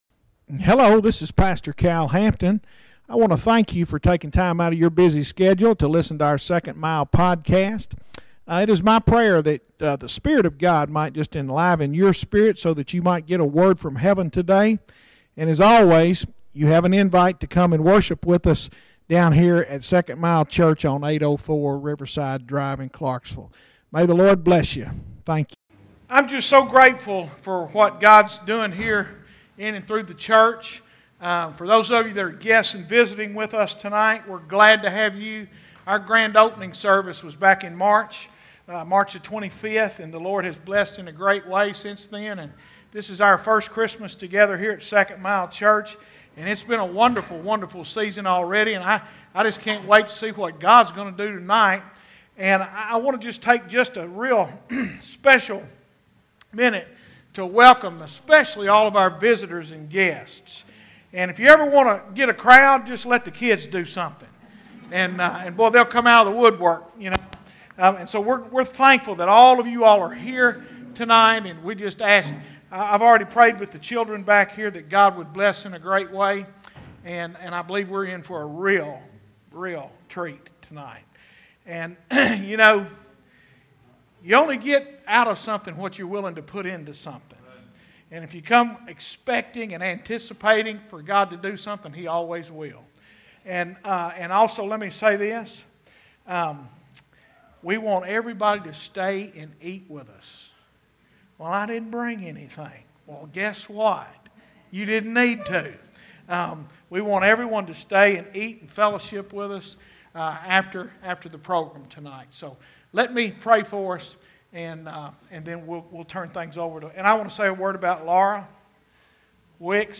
Sep 23, 2018 | by (All) | series: Sunday Evening Worship